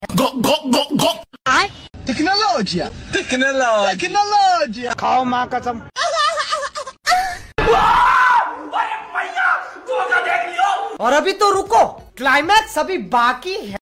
viral memes sounds effects. sound effects free download